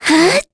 Talisha-Vox_Attack4_kr.wav